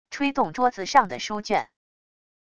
吹动桌子上的书卷wav音频